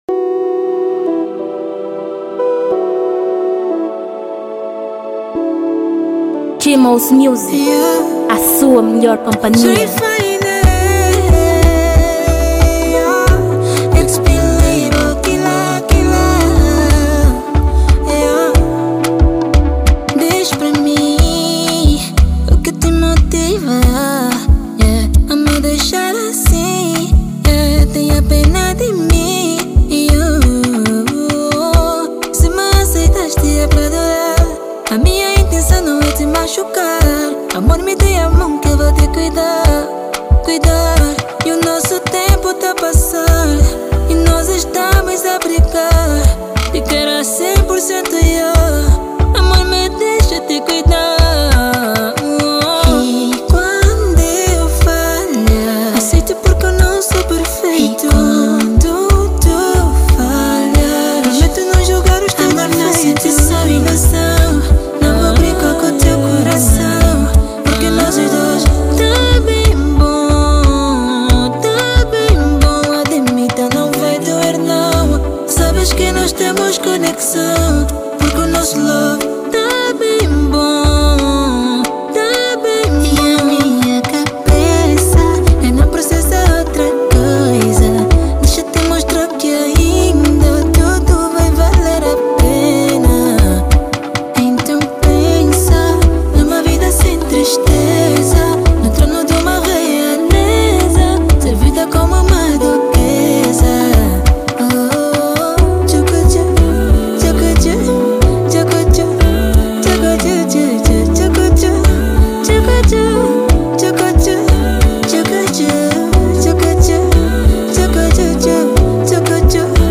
Gênero: Zouk